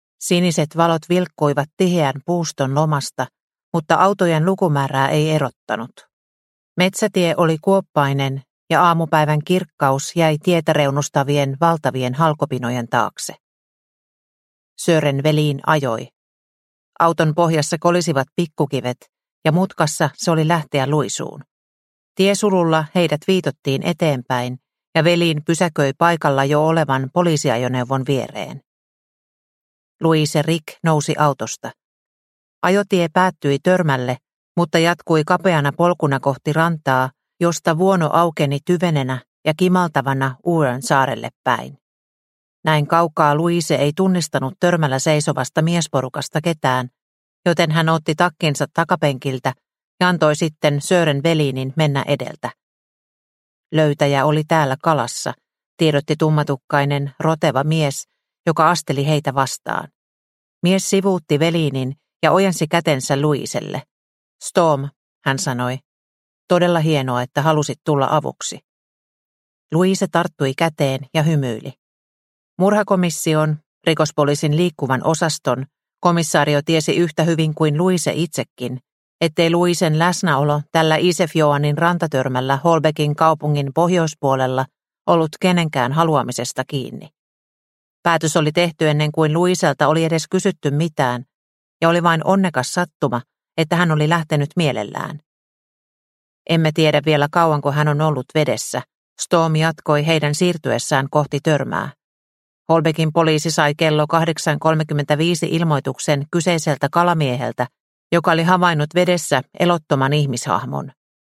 Vain yksi elämä – Ljudbok – Laddas ner